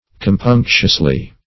Compunctiously \Com*punc"tious*ly\, adv.